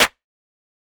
Snare 012.wav